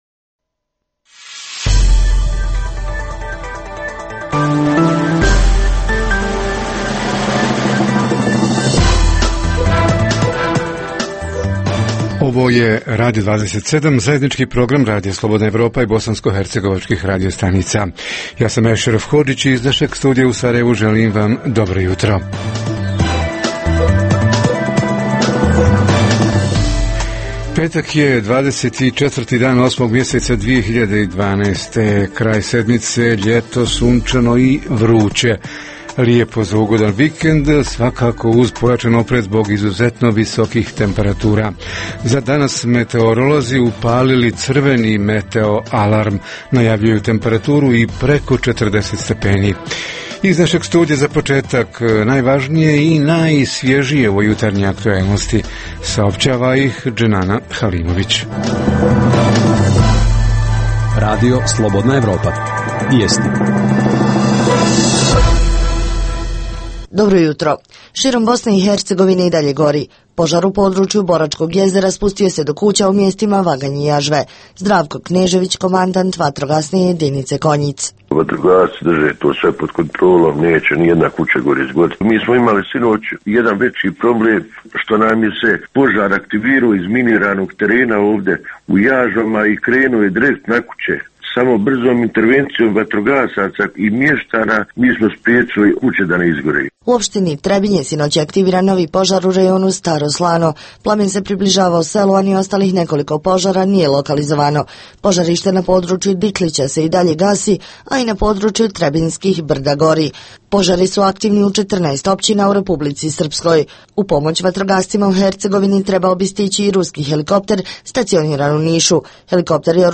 Tema jutarnjeg programa je kvalitet hrane i zaštita potrošača – ko i kako kontroliše hranu koja se nudi u bh.trgovinama i šta se poduzima kako bi se zaštitilo zdravlje potrošača? Osim toga, poslušajte informacije, teme i analize o dešavanjima u BiH i regionu, a reporteri iz cijele BiH javljaju o najaktuelnijim događajima u njihovim sredinama.
Redovni sadržaji jutarnjeg programa za BiH su i vijesti i muzika.